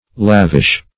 Lavish \Lav"ish\ (l[a^]v"[i^]sh), a. [Akin to E. lave to lade